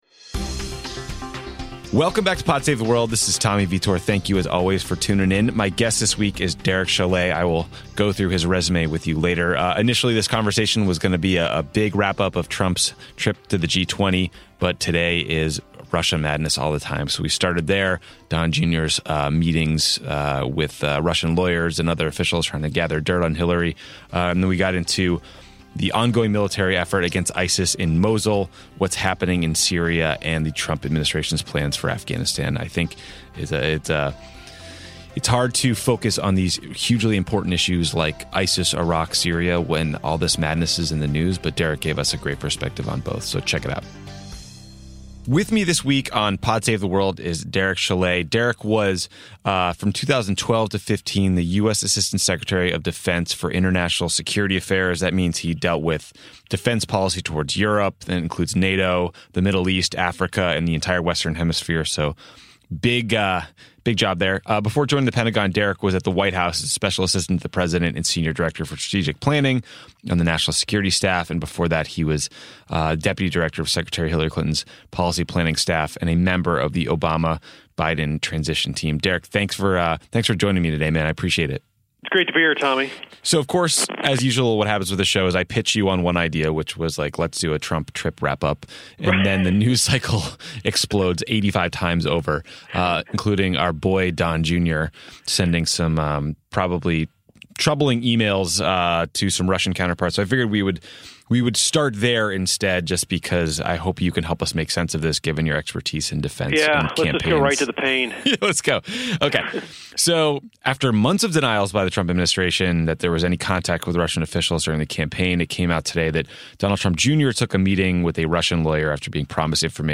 Tommy talks with former top defense official Derek Chollet about Donald Trump Jr.’s keystone cops collusion with Russia before transitioning to a conversation about the fight against ISIS in Mosul, Syria and the Trump administration’s policy in Afghanistan.